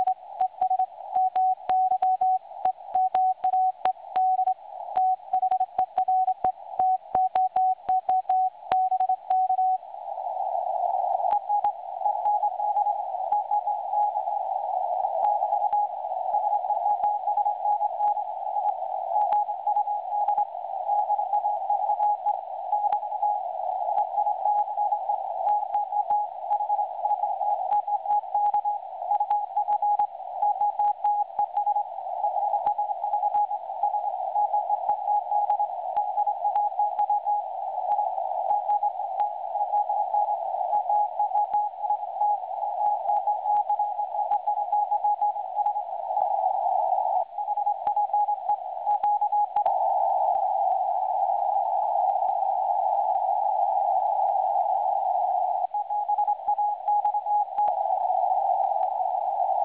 I’ve included a clip here from a QSO heard today. I wasn’t able to make out whole words, but definitely made out some of the characters, somewhat sporadically.